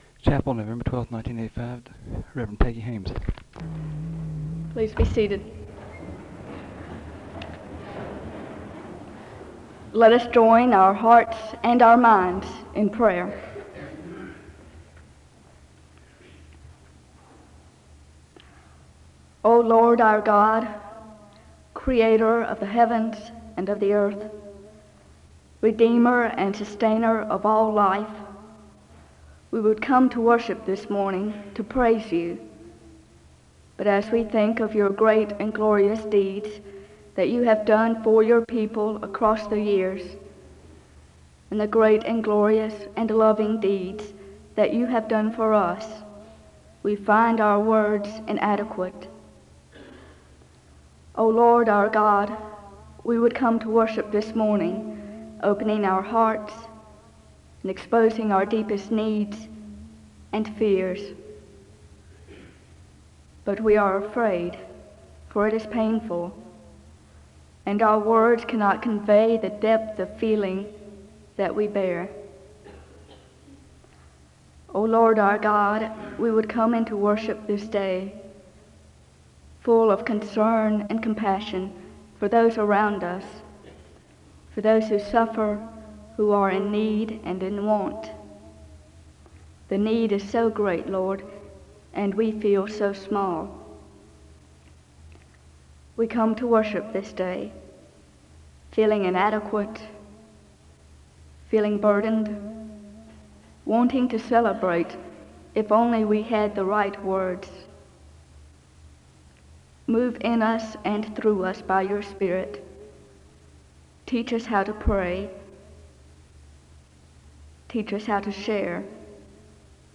The service begins with a word of prayer (00:00-02:09).
SEBTS Chapel and Special Event Recordings SEBTS Chapel and Special Event Recordings